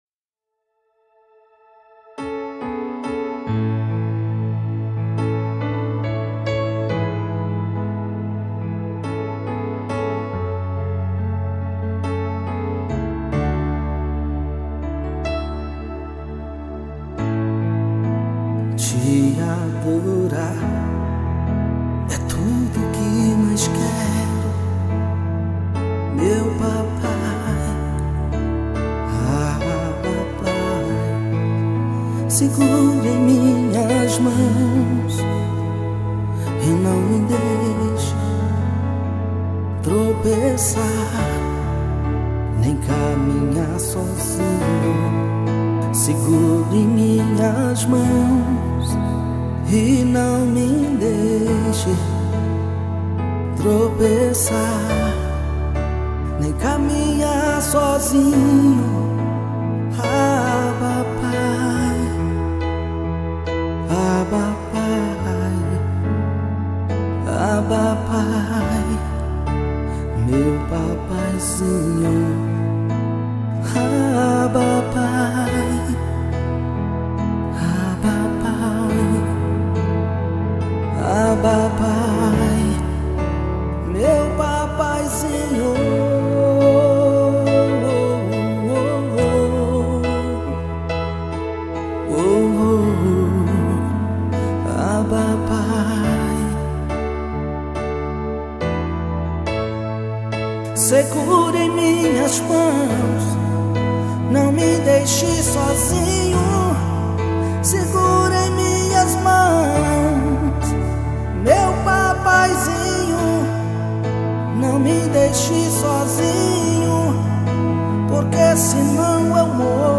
Com uma voz marcante e uma presença sincera